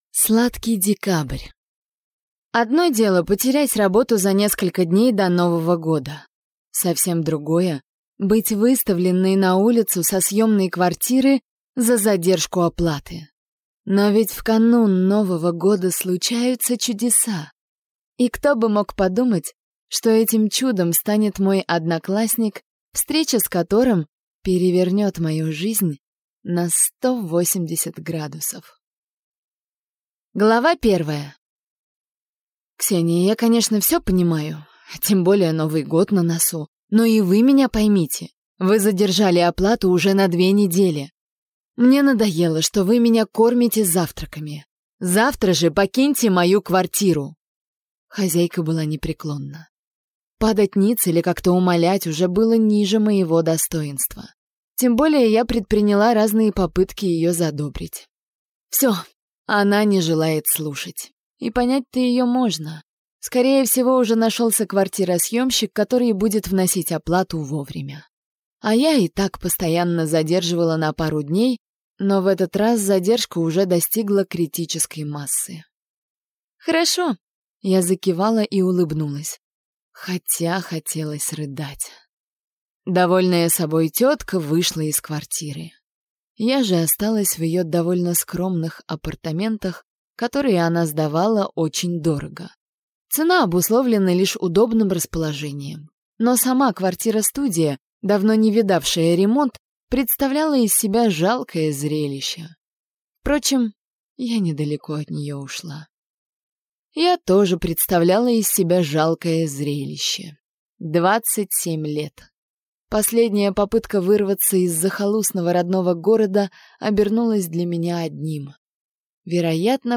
Аудиокнига Сладкий декабрь | Библиотека аудиокниг